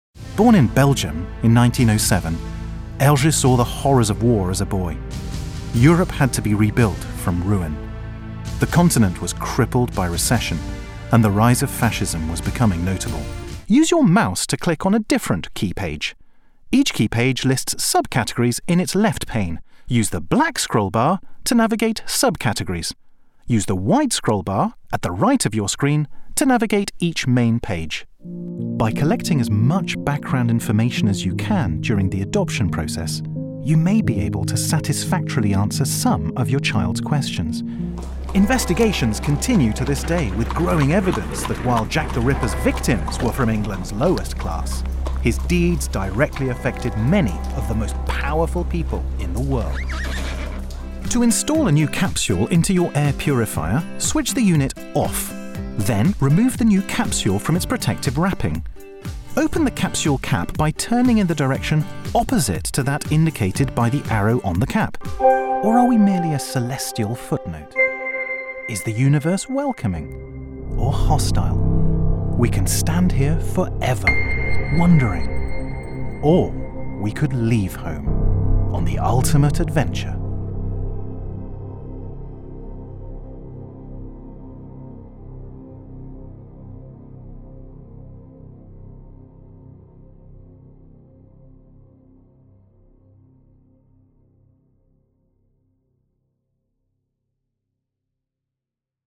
English (British)
Explainer Videos
Baritone
WarmFriendlyReliableAssuredEngaging